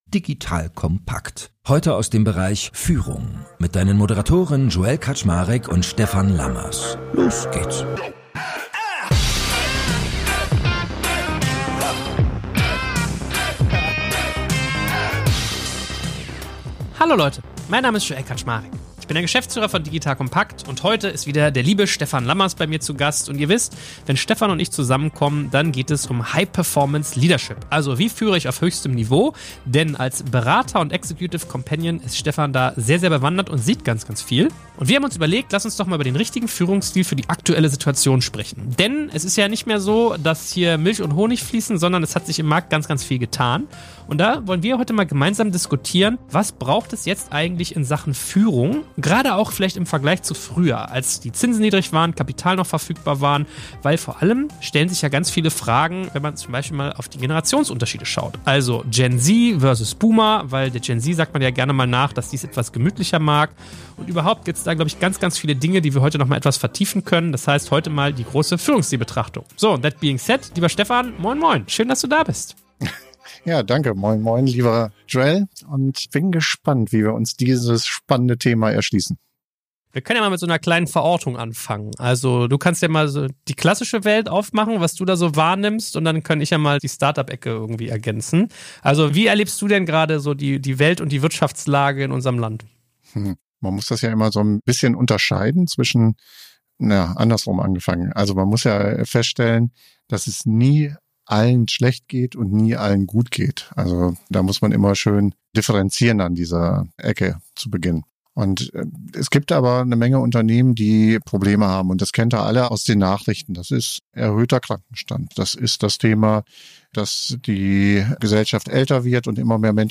Genau darum geht es in unserem heutigen Expertengespräch